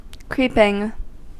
Ääntäminen
Ääntäminen US : IPA : [ˈkɹi.pɪŋ] Tuntematon aksentti: IPA : /ˈkɹiː.pɪŋ/ Haettu sana löytyi näillä lähdekielillä: englanti Creeping on sanan creep partisiipin preesens.